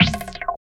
22 CONGAS -R.wav